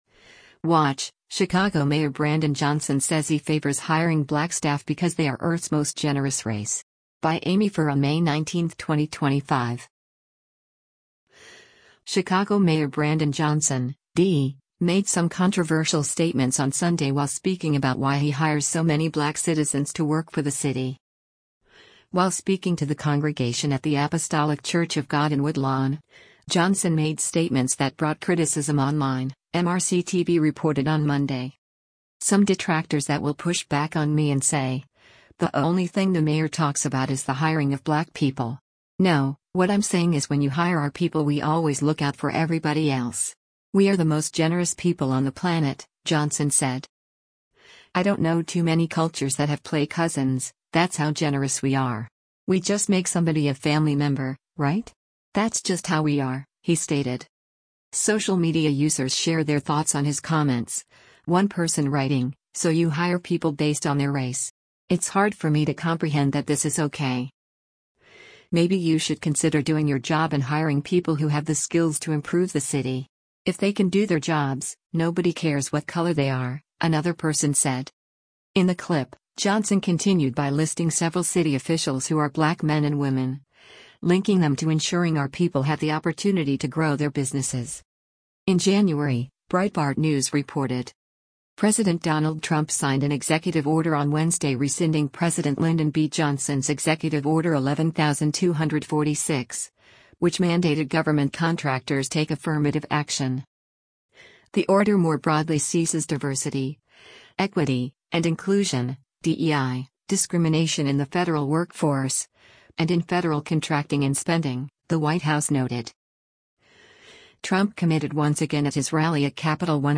While speaking to the congregation at the Apostolic Church of God in Woodlawn, Johnson made statements that brought criticism online, MRC-TV reported on Monday.